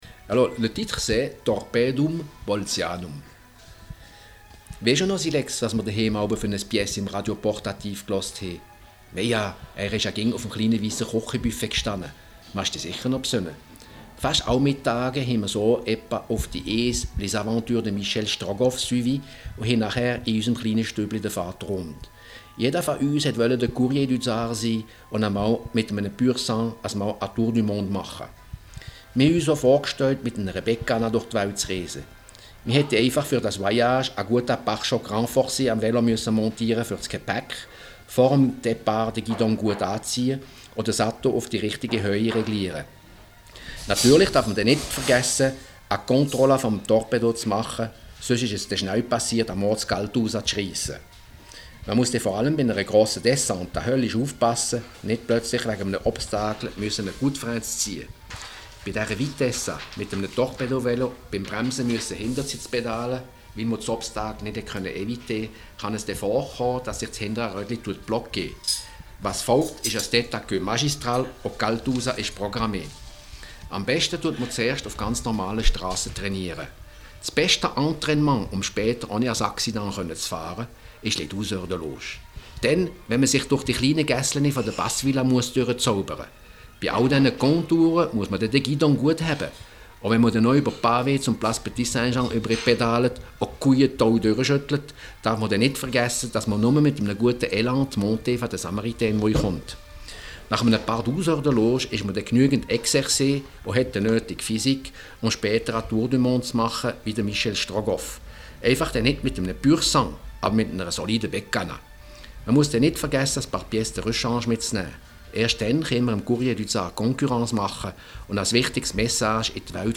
Les aventures de Michel Strogoff en bolze